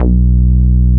SPIKE BASS 4.wav